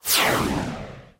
powerChangeGravity.wav